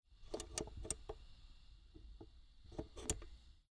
Portable radio TS502
CAF on off
caf-on-off.mp3